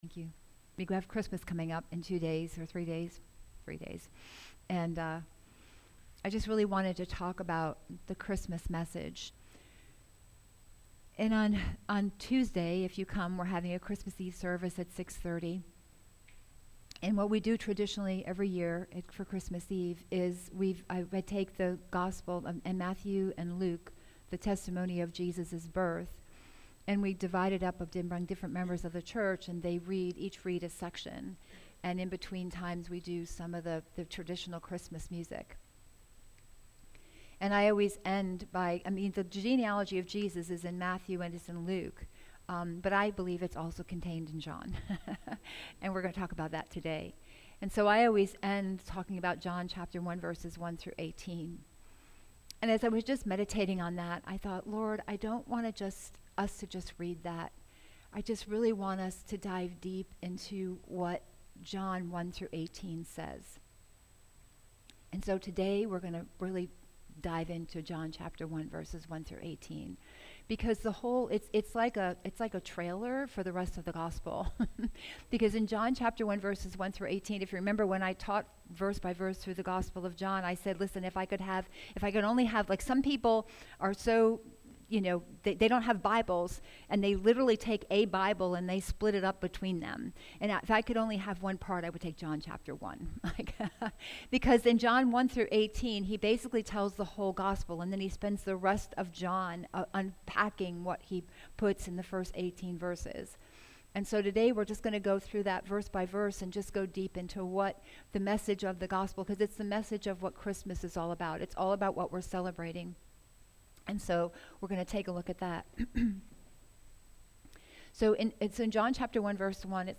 Messages | Kingdom Life Church International